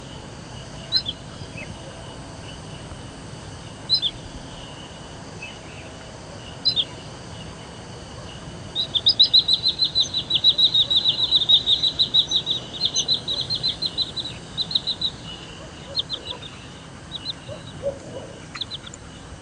林鹬鸟叫声